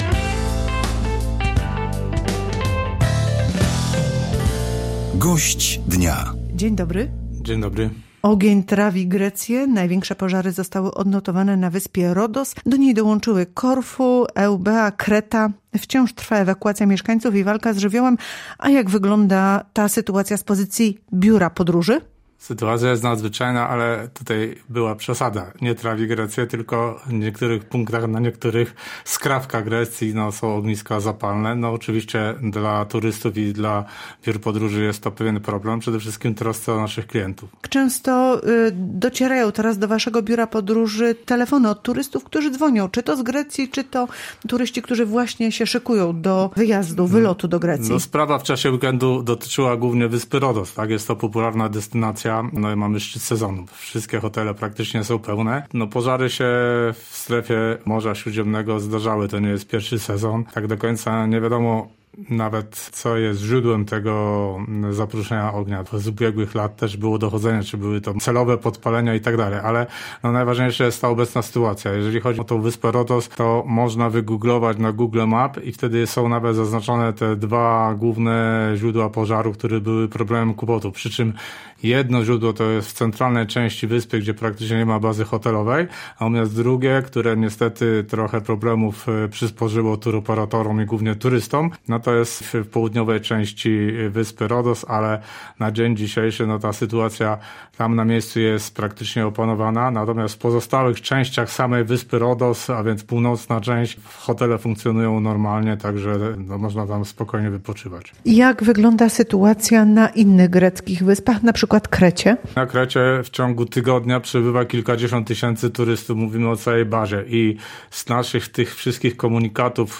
Gość dnia • Polskie Radio Rzeszów